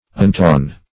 Search Result for " untongue" : The Collaborative International Dictionary of English v.0.48: Untongue \Un*tongue\, v. t. [1st pref. un- + tongue.]